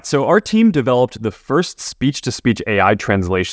XTTS_V2_Finetuned_Voice_Cloning like 0